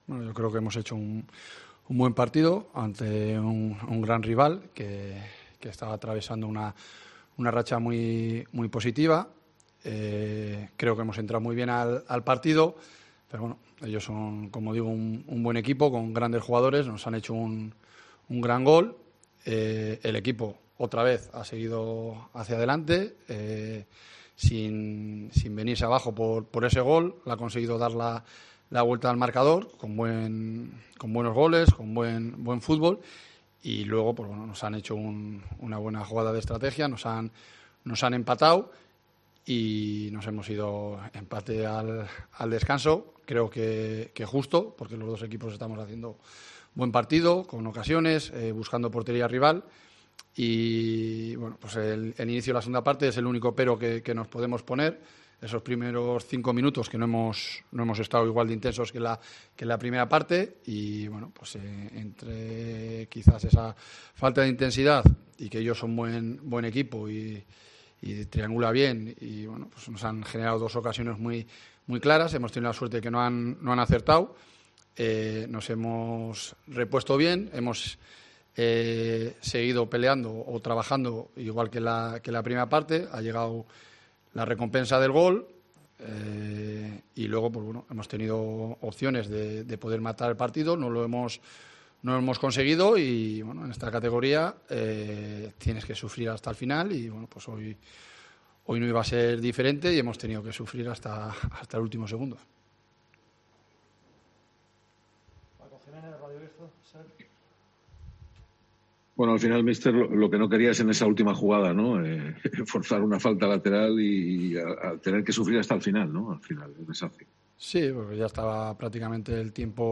Lee y escucha aquí al entrenador de la Deportiva tras la victoria 3-2 ante el Leganés